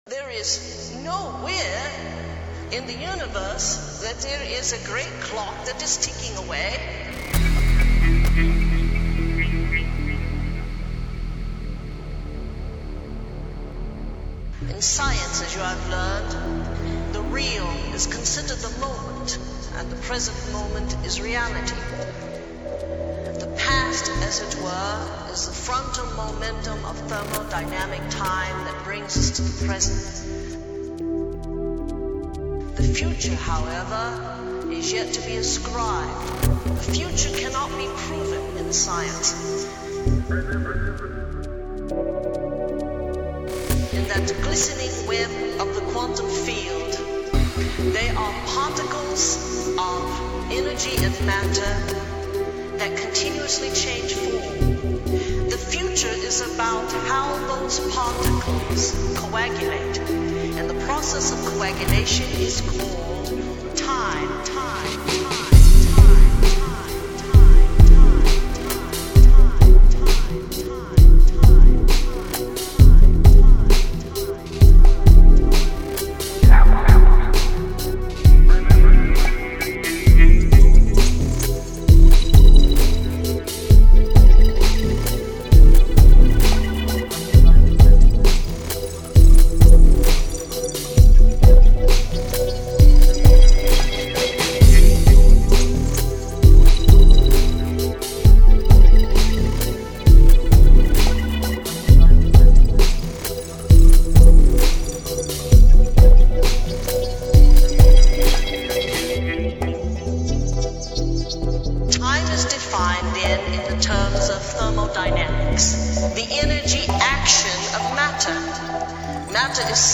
Deep techno, ambient/dub
In the Glistening web of the quantum fields (dub) or